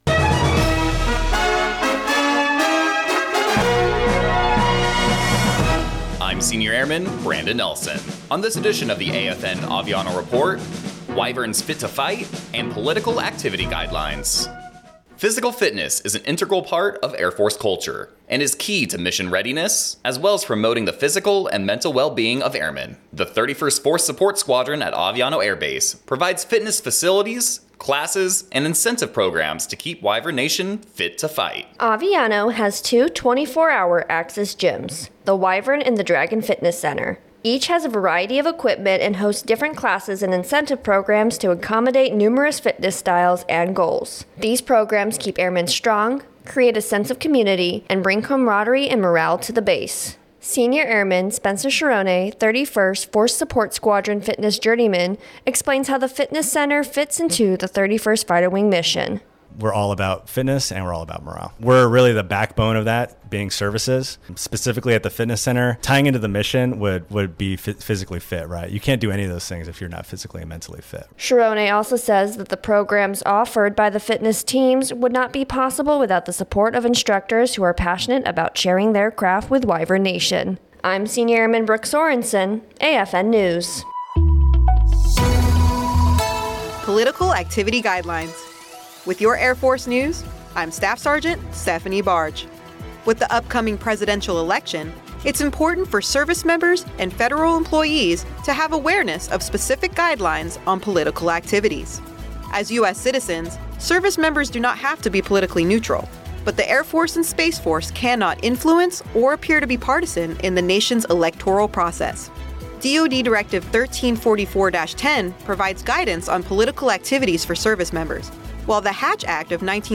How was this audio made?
American Forces Network (AFN) Aviano radio news reports on the 31st Force Support Squadron and the fitness facilities, classes and incentive programs they provide to keep Wyvern Nation fit to fight.